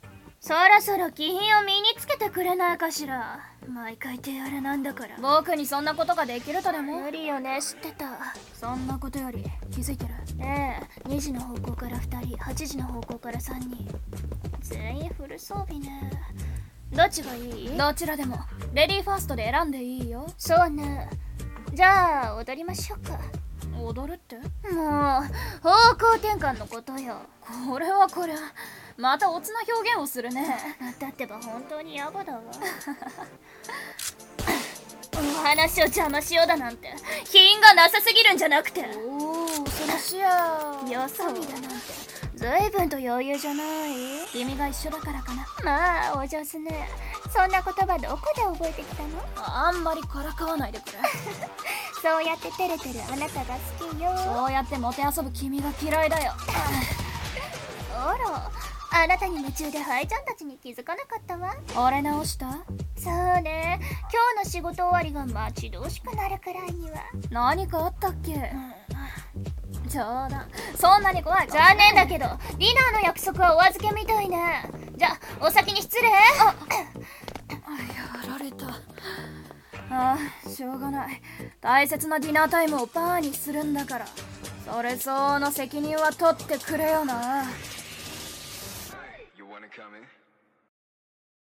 【声劇】殺し屋たちのワルツ